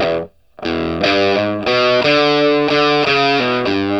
WALK1 60 E.A.wav